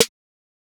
TS Snare_17.wav